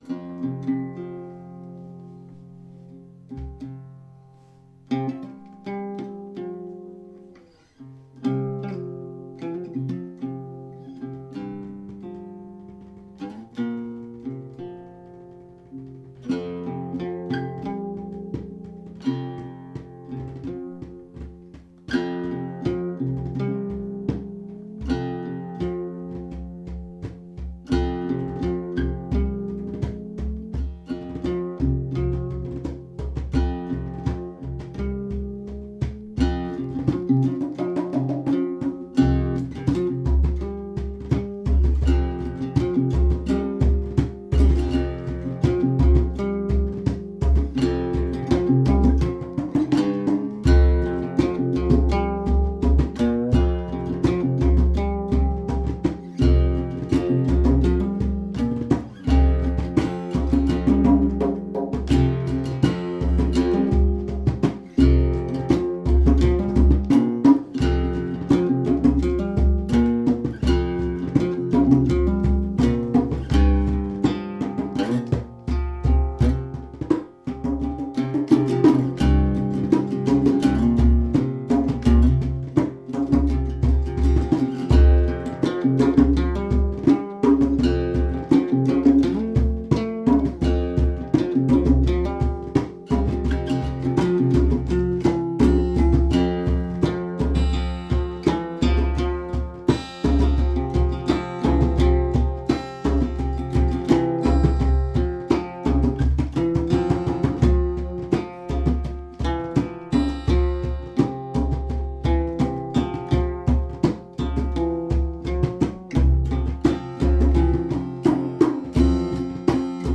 Day 46 Jam
day 46 hand drum and guitar [ 4:02 ] Play Now | Play in Popup | Download